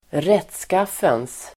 Uttal: [²r'et:skaf:ens]